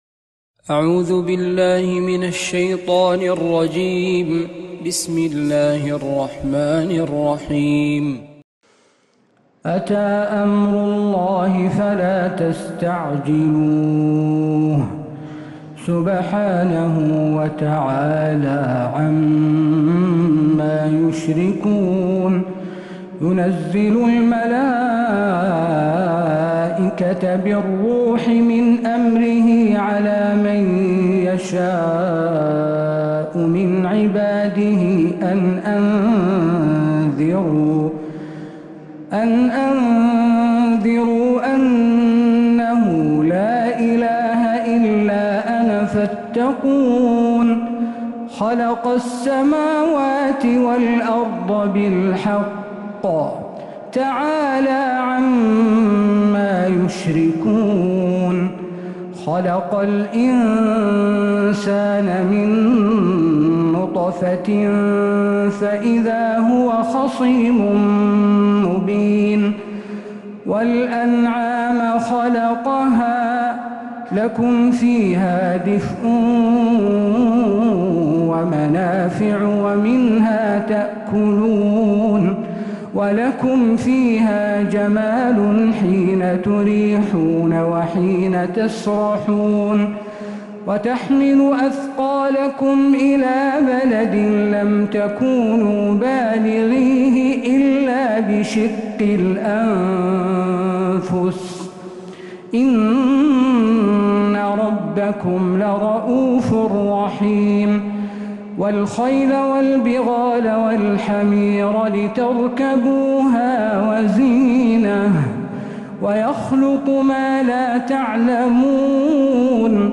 السور المكتملة 🕌